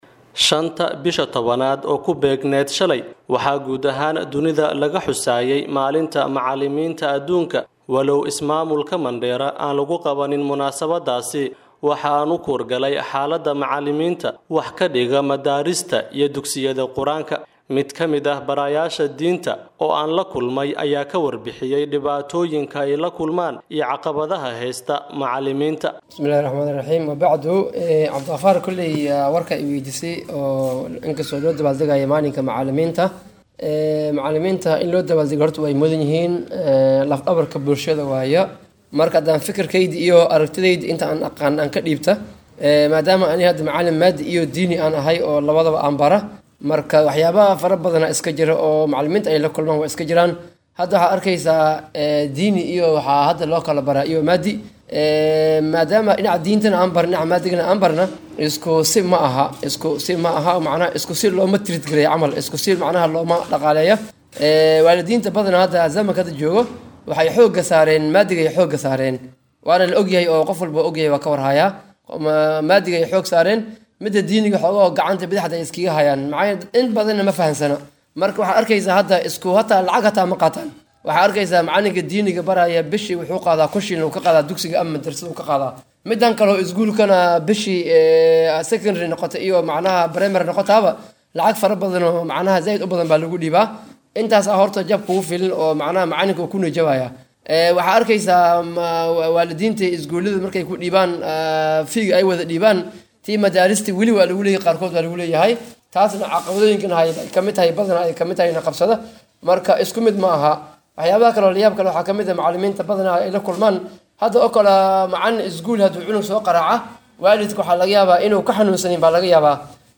DHAGEYSO:Warbixin:Caqabado sidee ayay la kulmaan macallimiinta diinta ee Mandera?